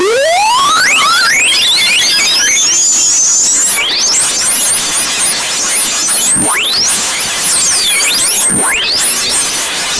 page-flip-soft-documentar-sqyib7sp.wav